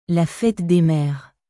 La fête des mèresラ フェトゥ デ メール